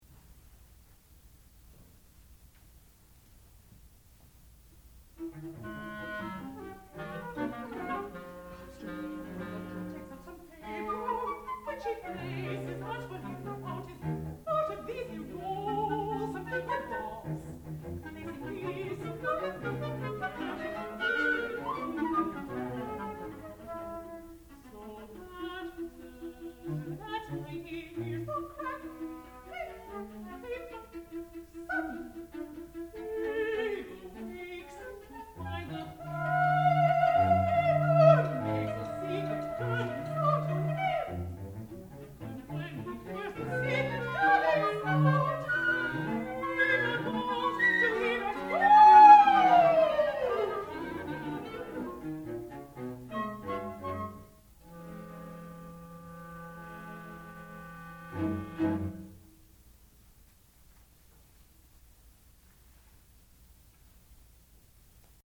sound recording-musical
classical music
Cambiata Soloists (performer).